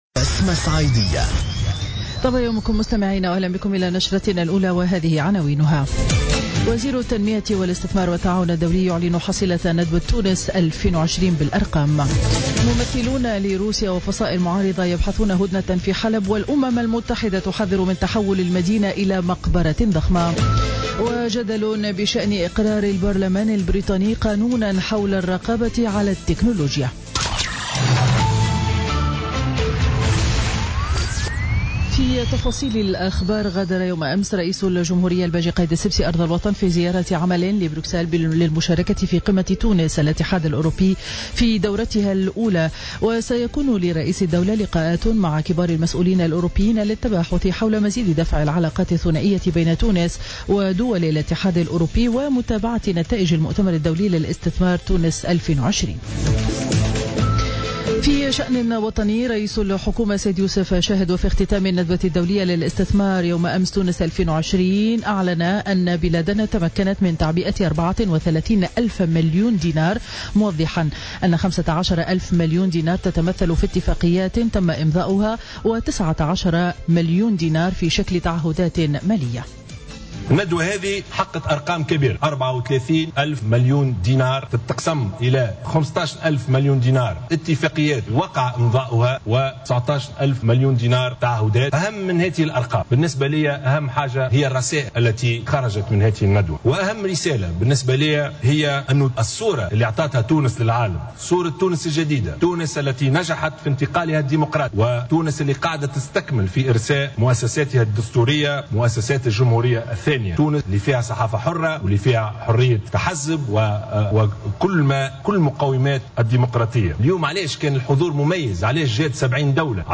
نشرة أخبار السابعة صباحا ليوم الخميس 1 ديسمبر 2016